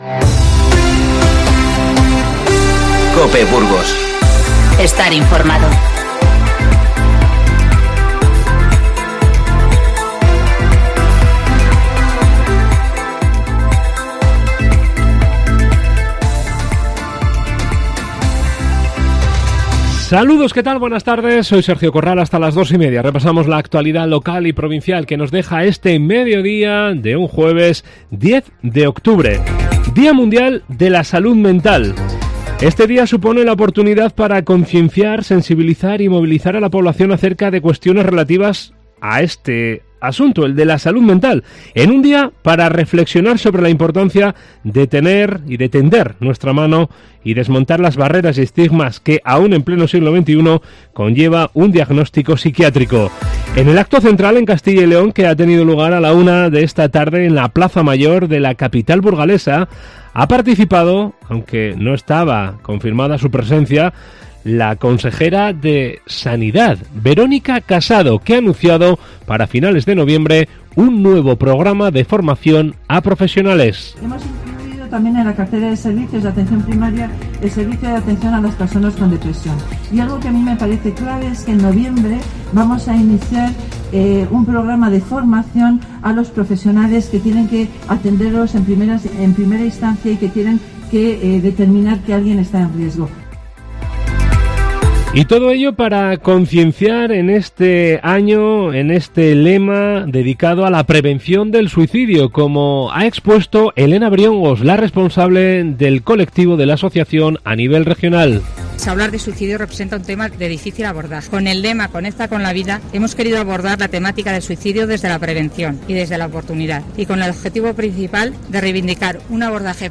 INFORMATIVO Mediodía 10-10-19